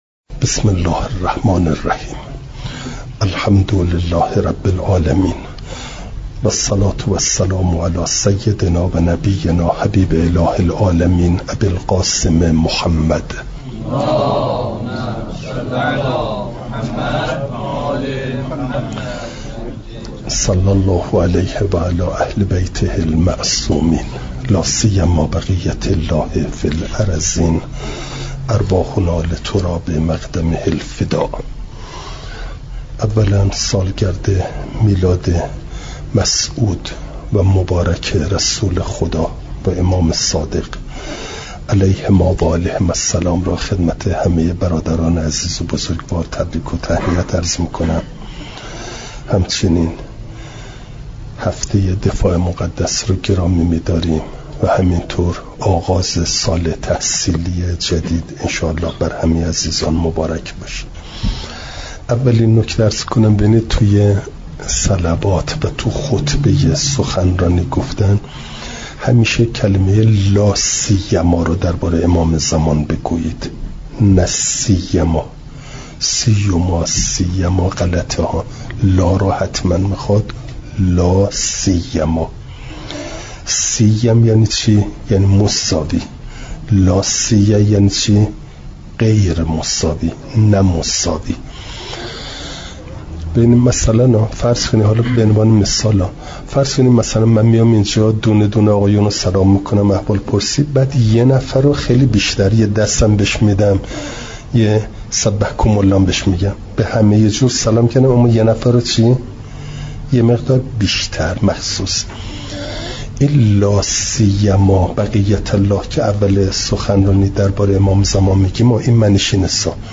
بیانات اخلاقی
دوشنبه ۲ مهرماه ۱۴۰۳، حوزه علمیه حضرت ابوالفضل علیه السلام(جانبازان)